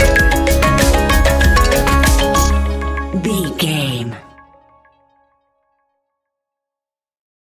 Ionian/Major
C♯
electronic
techno
trance
synths